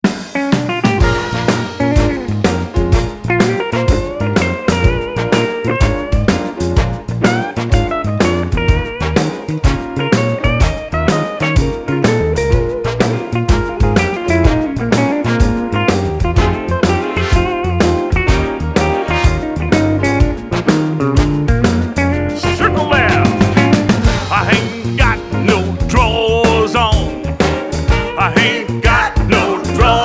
Singing Call
Voc